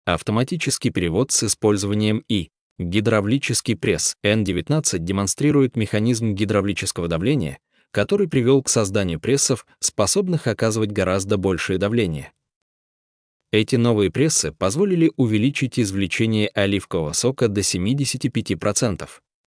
Аудиогид